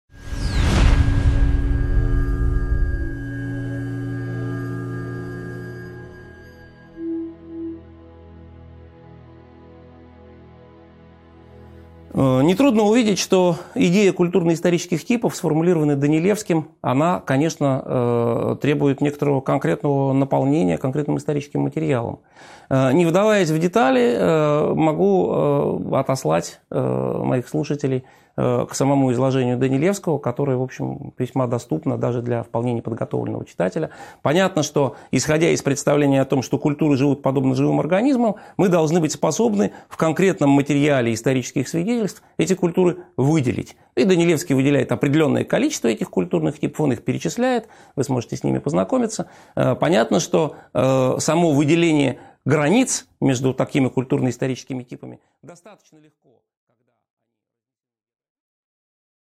Аудиокнига 13.5 Культурная морфология | Библиотека аудиокниг